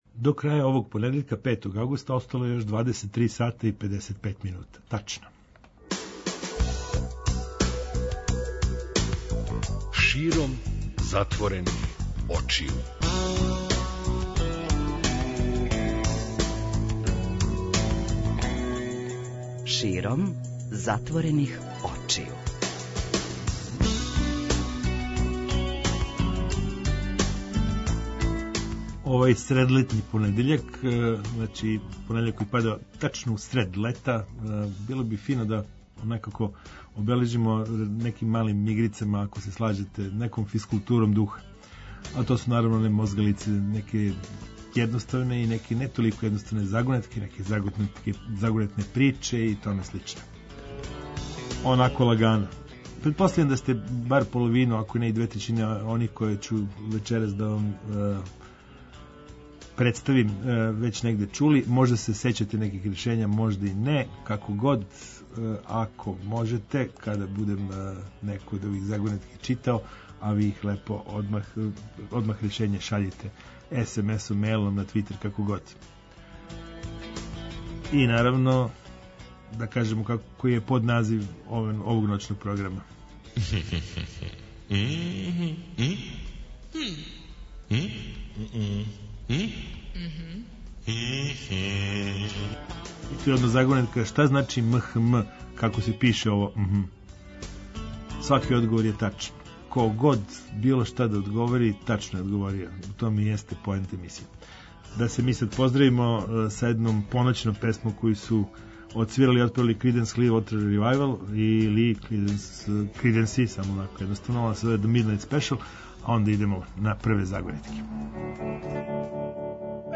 преузми : 50.14 MB Широм затворених очију Autor: Београд 202 Ноћни програм Београда 202 [ детаљније ] Све епизоде серијала Београд 202 Тешке боје Устанак Устанак Устанак Брза трака